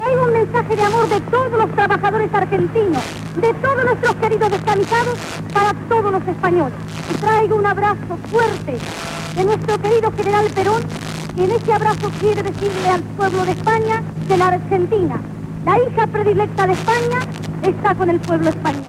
Salutació de la política argentina María Eva Duarte de Perón.
Extret de Crònica Sentimental de Ràdio Barcelona emesa el dia 15 d'octubre de 1994